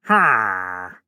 Minecraft Version Minecraft Version 1.21.5 Latest Release | Latest Snapshot 1.21.5 / assets / minecraft / sounds / mob / wandering_trader / no3.ogg Compare With Compare With Latest Release | Latest Snapshot